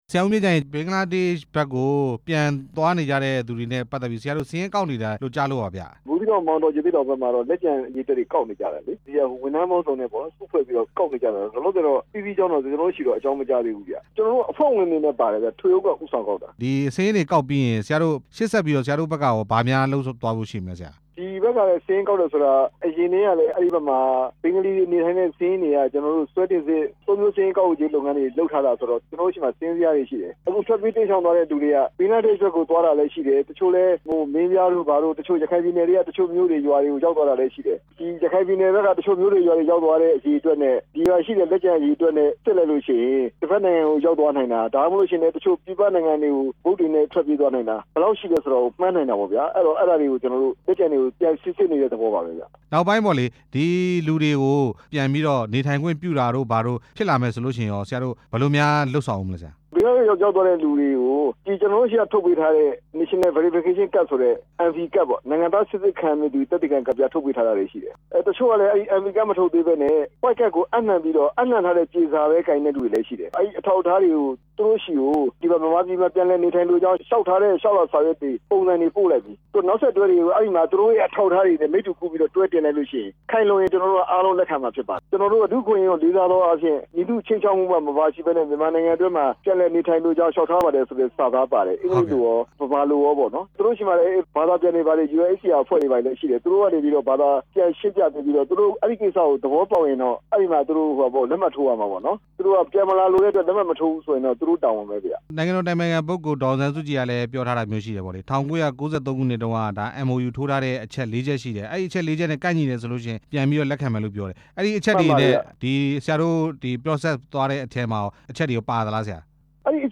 ဒုက္ခသည်တွေကို ပြန်ခေါ်ရေး အစီအစဉ် မေးမြန်းချက်